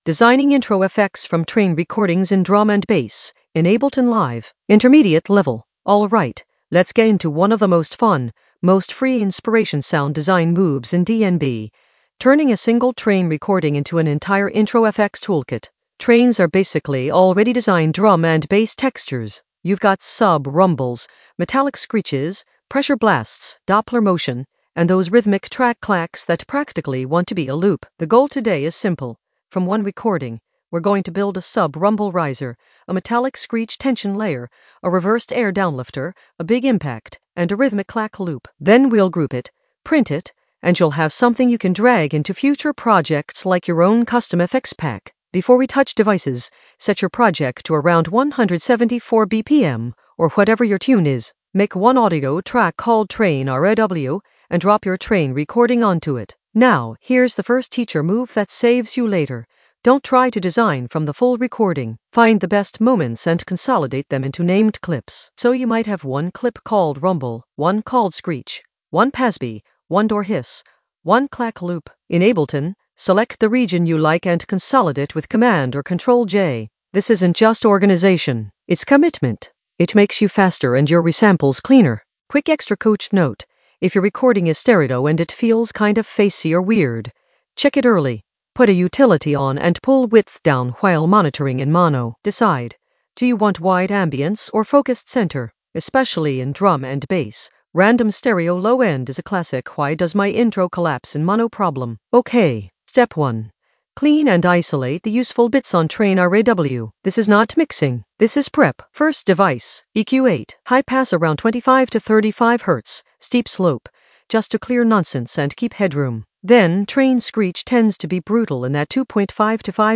Narrated lesson audio
The voice track includes the tutorial plus extra teacher commentary.
An AI-generated intermediate Ableton lesson focused on Designing intro FX from train recordings in the Sound Design area of drum and bass production.